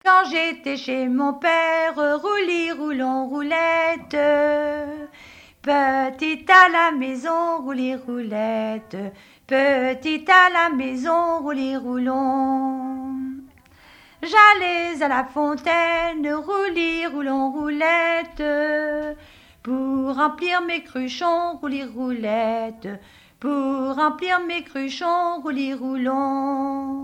Mémoires et Patrimoines vivants - RaddO est une base de données d'archives iconographiques et sonores.
Témoignages sur le mariage et chansons traditionnelles
Pièce musicale inédite